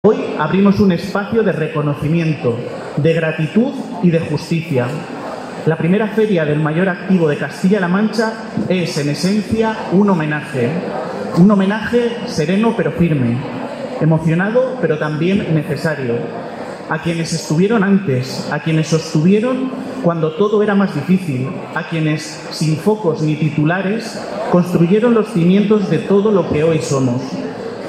Declaraciones del alcalde Miguel Óscar Aparicio 1
El presidente regional y el alcalde de Azuqueca han asistido este viernes a la inauguración de la primera Feria del Mayor Activo